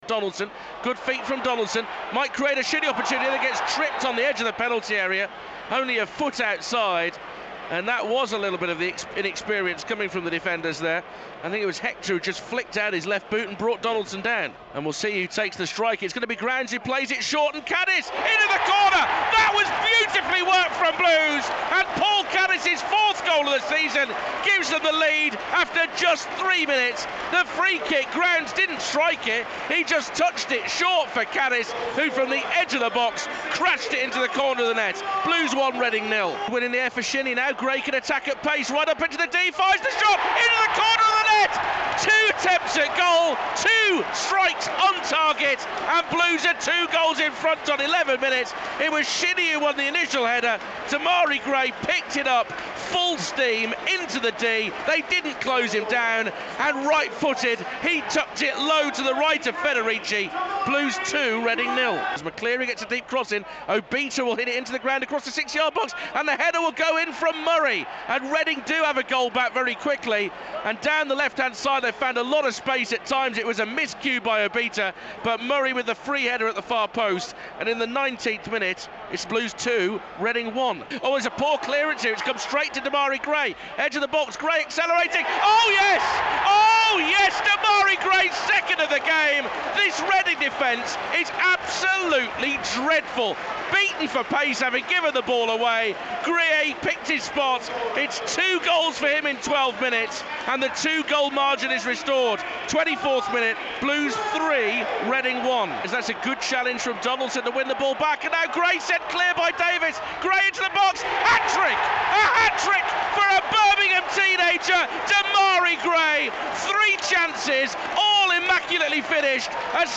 describes the action and talks to Gary Rowett post-match at St. Andrew's.